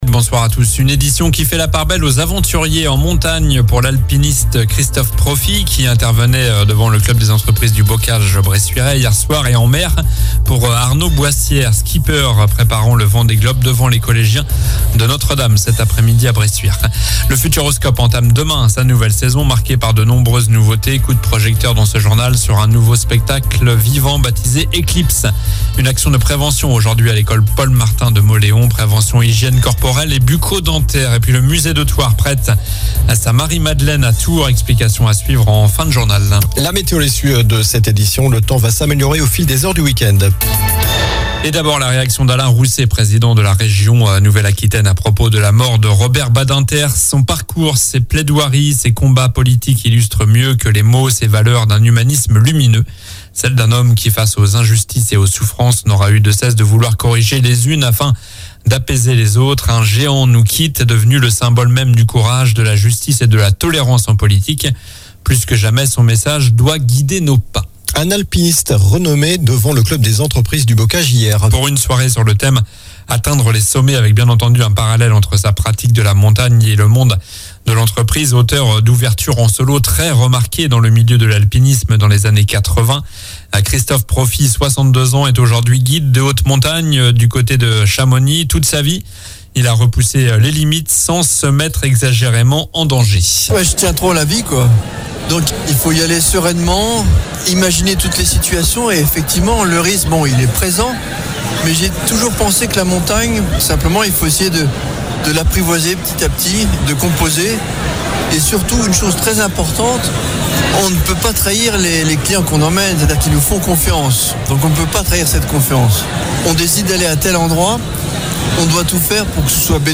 Journal du vendredi 09 février (soir)